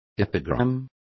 Complete with pronunciation of the translation of epigram.